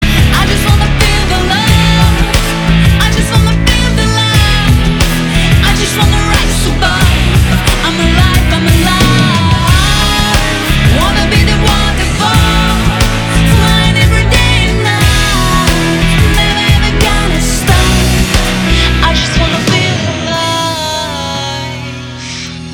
• Качество: 320, Stereo
громкие
красивый женский вокал
поп-рок